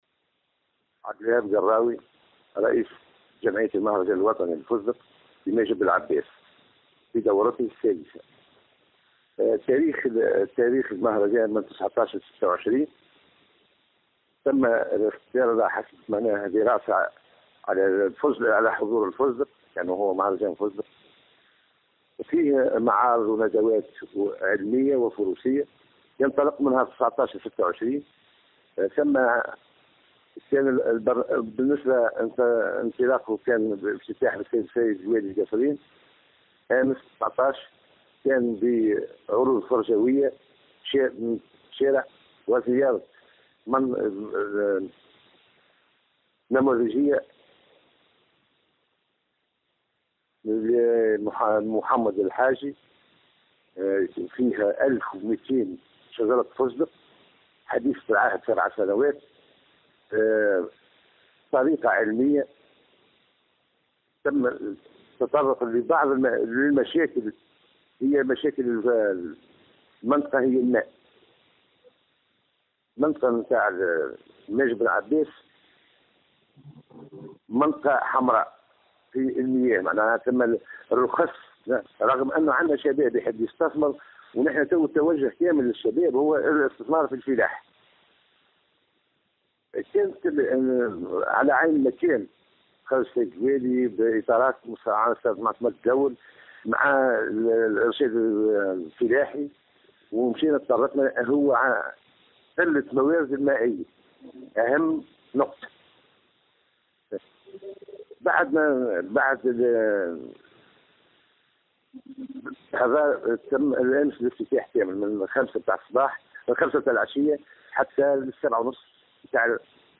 مزيد التفاصيل في التصريح التالي: